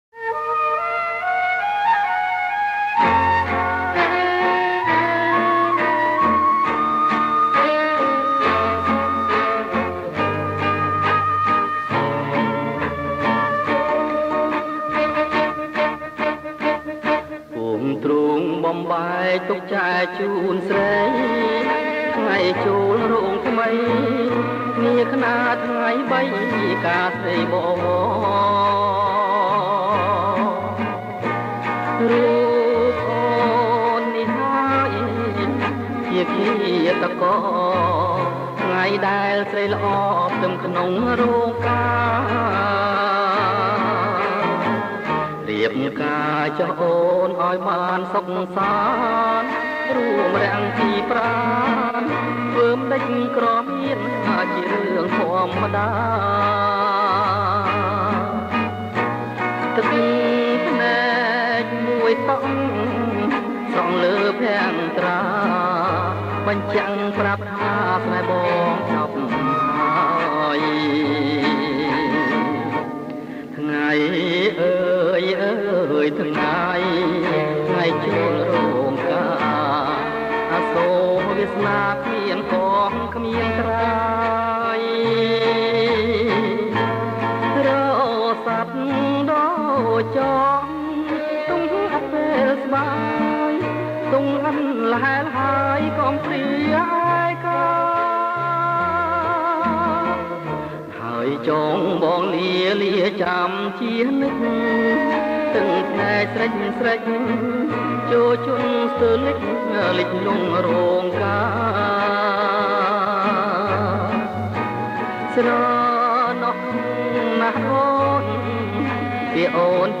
ប្រគំជាចង្វាក់